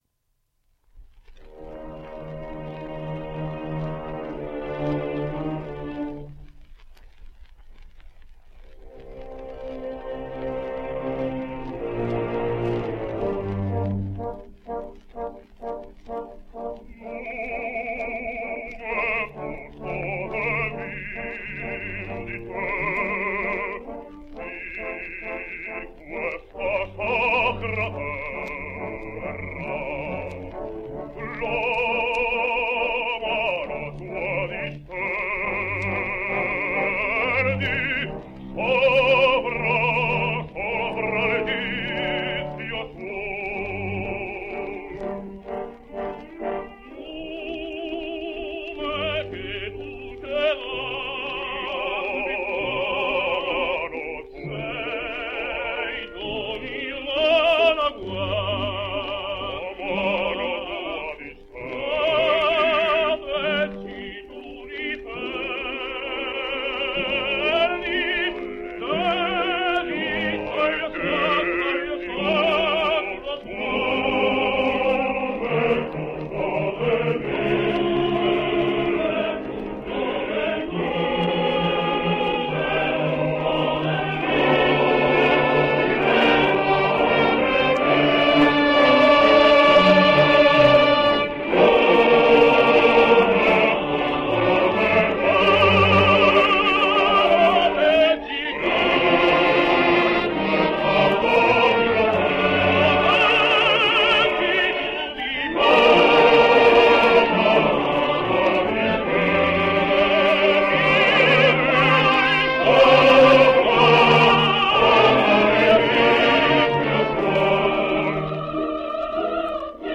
Italian tenor.
Here is a sample of his Radamez from Aida. Part of the temple scene, with that splendid Italian base, dan cride passero.